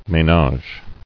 [mé·nage]